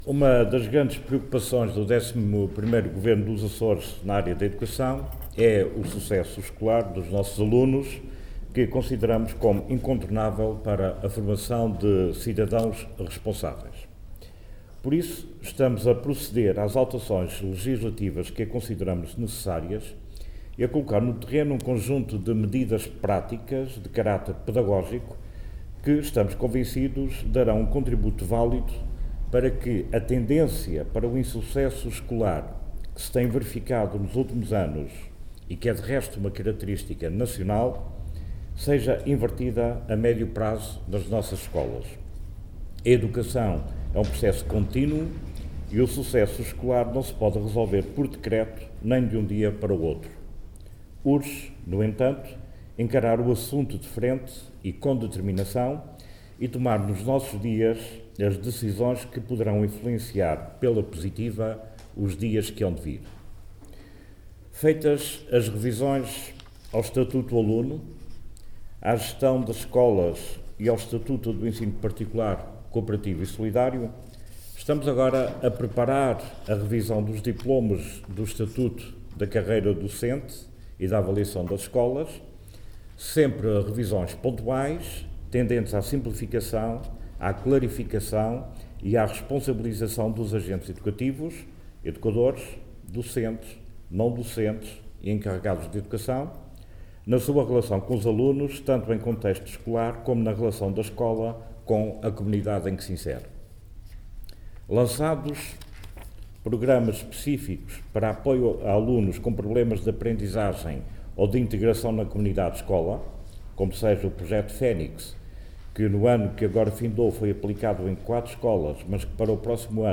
Luiz Fagundes Duarte,  que falava em Angra do Heroísmo sobre as alterações ao Programa Oportunidade hoje publicadas em Jornal Oficial, relembrou que “a educação é um processo contínuo, e o sucesso escolar não se pode resolver por decreto, nem de um dia para o outro”.